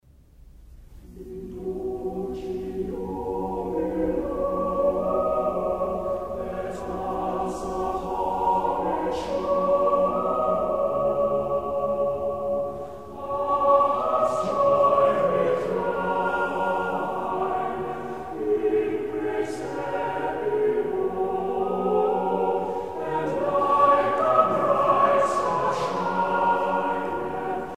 circonstance : Noël, Nativité
Pièce musicale éditée